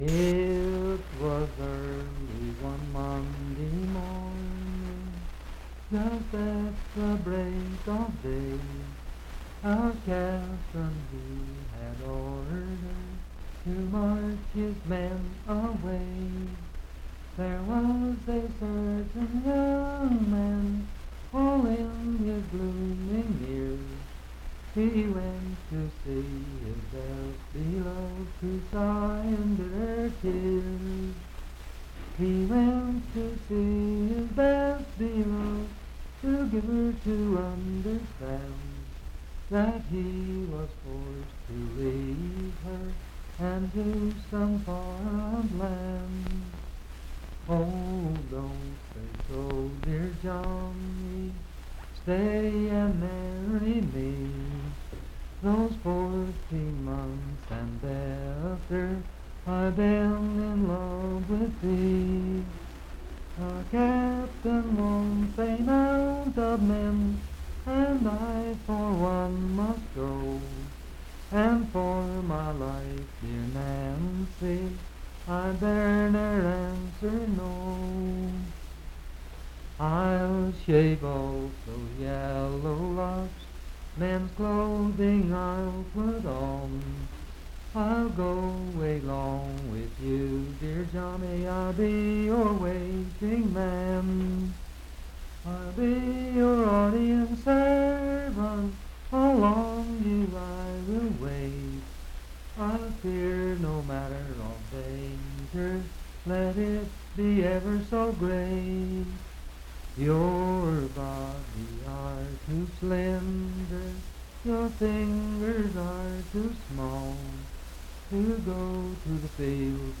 Unaccompanied vocal music
Verse-refrain 6d(6).
Voice (sung)
Pocahontas County (W. Va.), Marlinton (W. Va.)